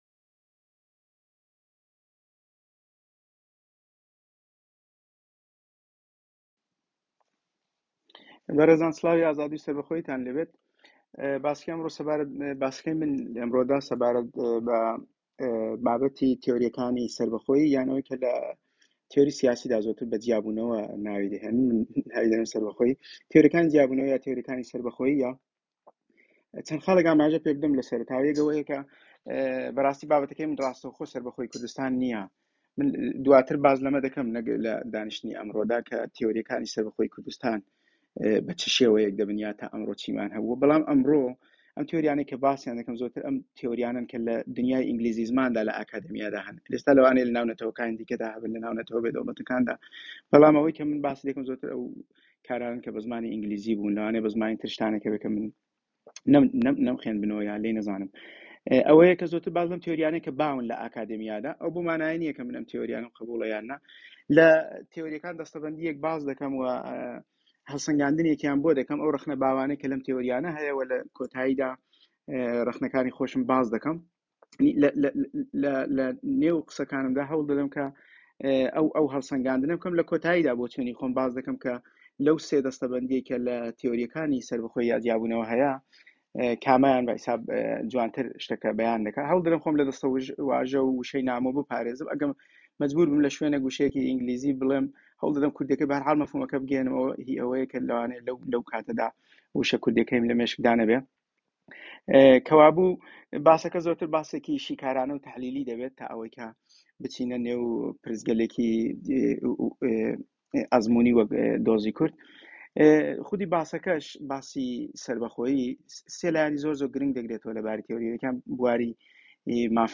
ناوی سەمینار: تیۆرییەکانی سەربەخۆیی. کڵابهاوس.